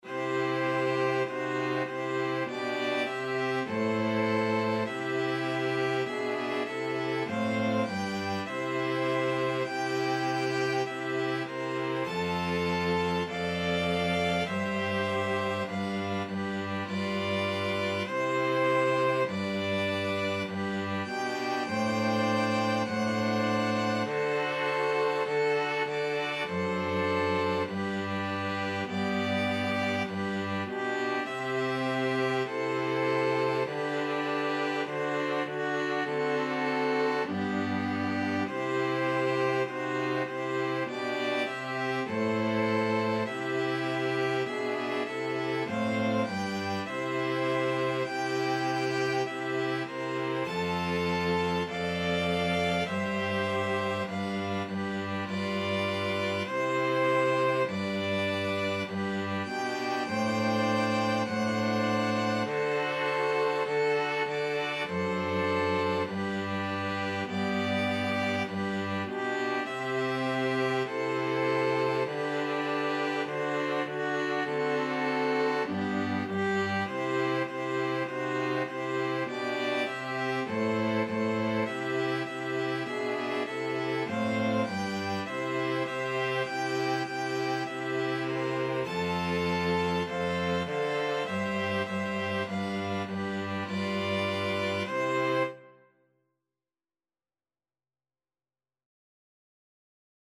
Ein Keloheinu (Jewish Trad.) Free Sheet music for String Quartet
String Quartet version String Quartet Traditional String Quartet Traditional String Quartet Free Sheet Music Ein Keloheinu (Jewish Trad.)
Violin 1 Violin 2 Viola Cello